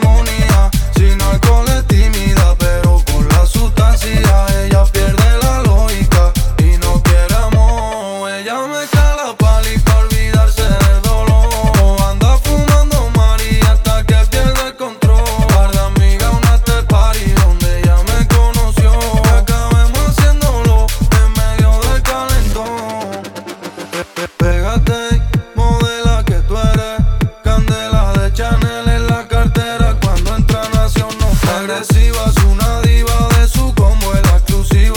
Жанр: Техно